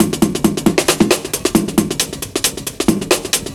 Mickey Break 135.wav